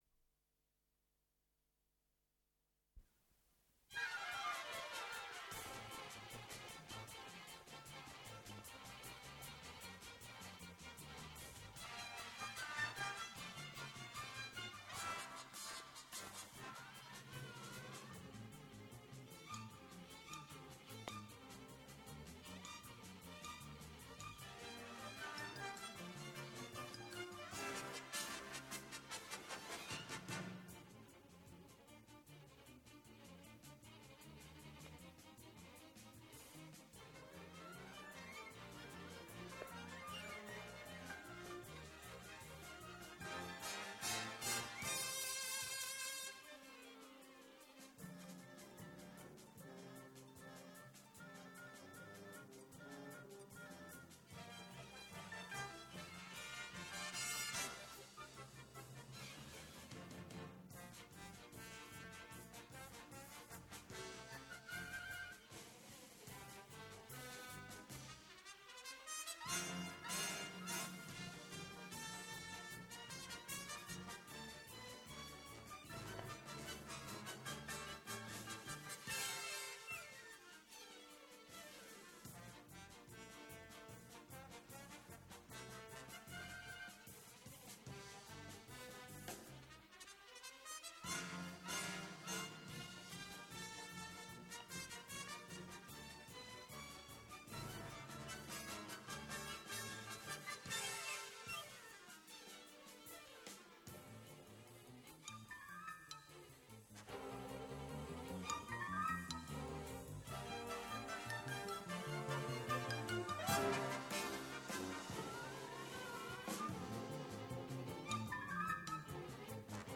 Галоп
Дубль моно